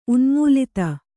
♪ unmūlita